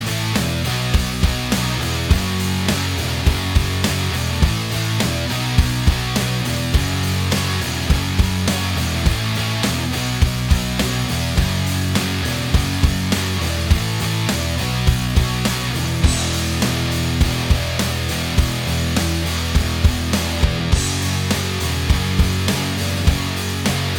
Minus All Guitars Indie / Alternative 4:52 Buy £1.50